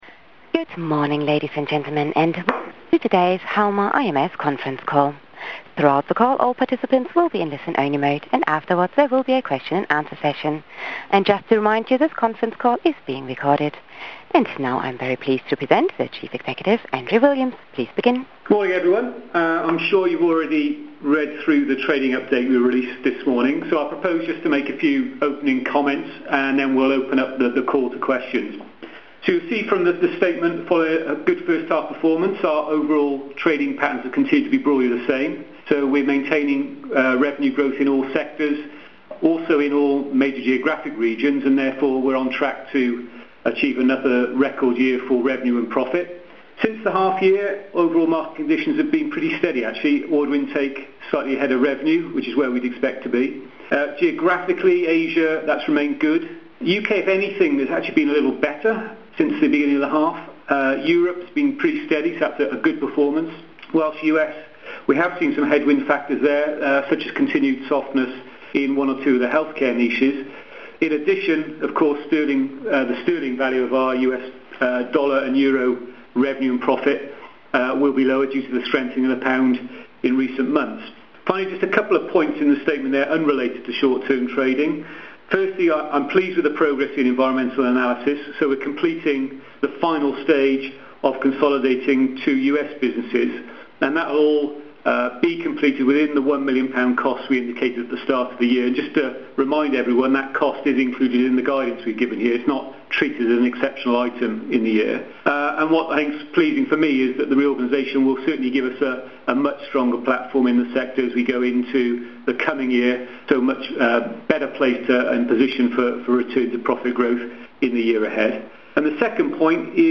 Conference call
halma-ims-conference-call-feb-2014.mp3